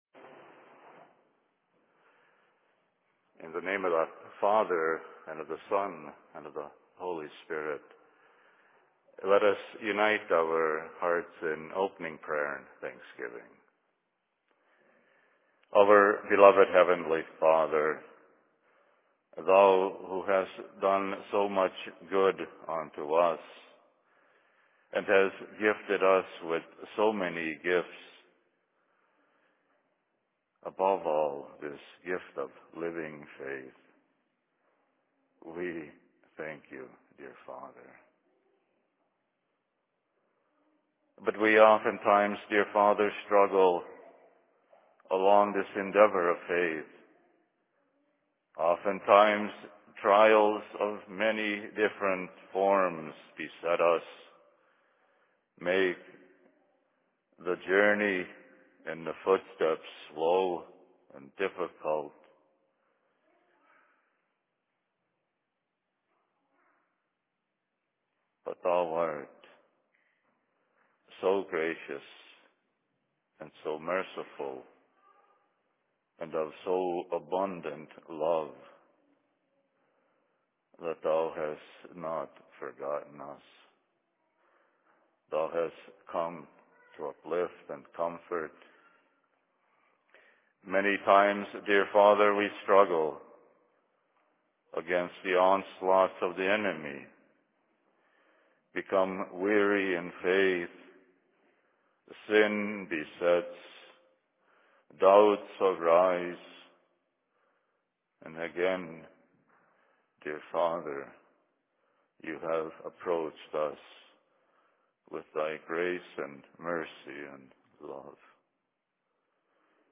Sermon in Cokato 22.04.2012
Location: LLC Cokato